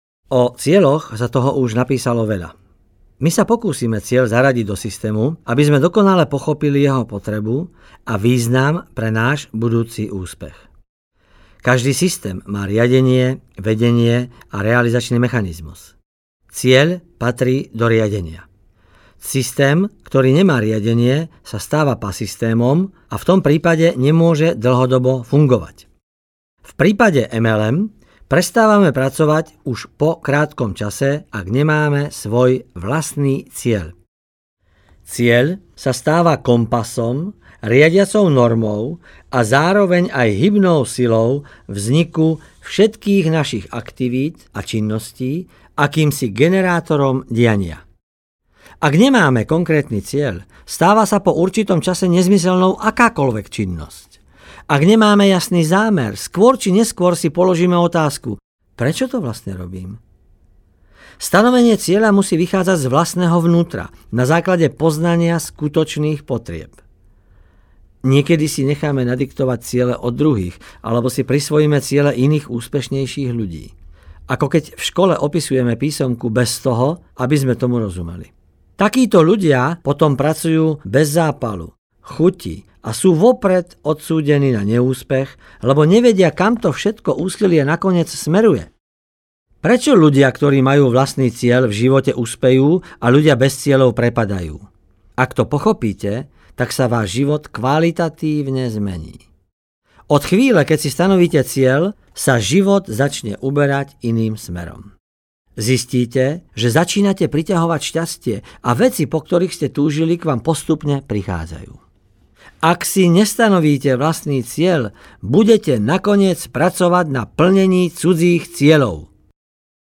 Šlabikár Multi Level Marketingu audiokniha